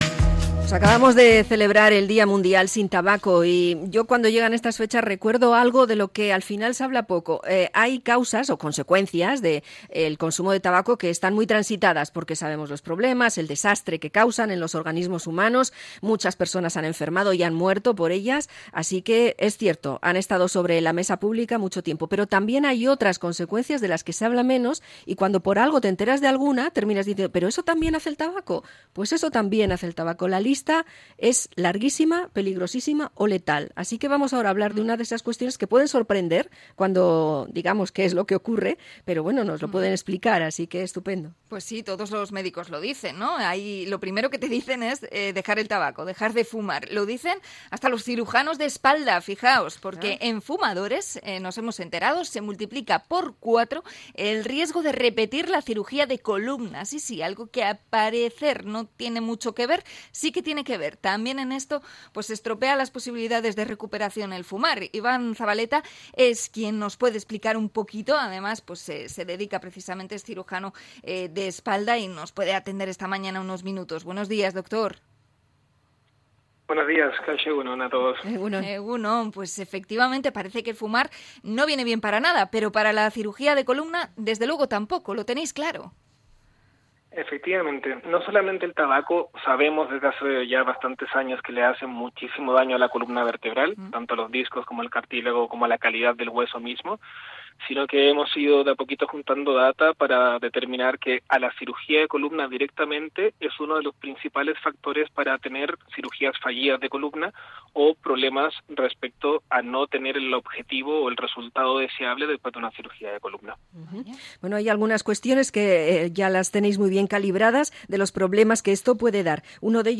Entrevista a cirujano por las operaciones de espalda